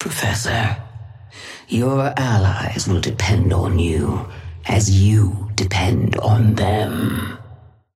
Sapphire Flame voice line - Professor, your allies will depend on you as you depend on them.
Patron_female_ally_dynamo_start_05.mp3